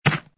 fs_ml_stone04.wav